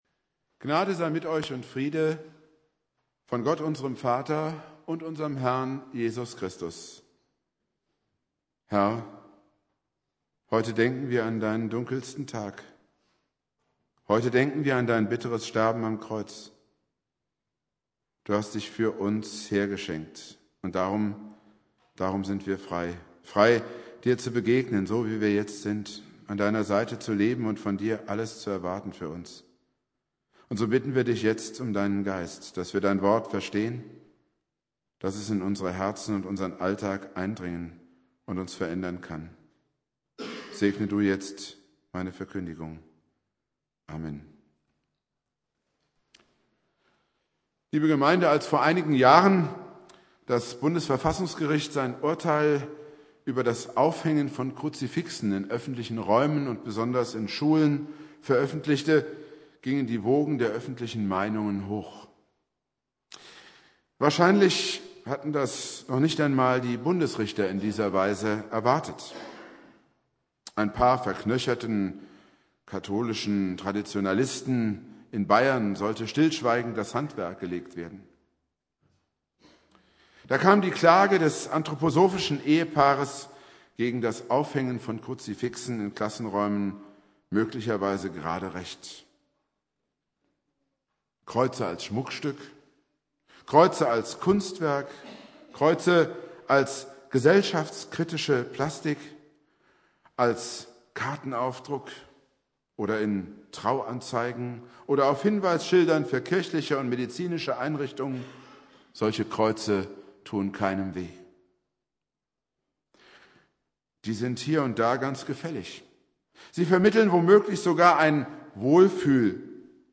Predigt
Karfreitag Prediger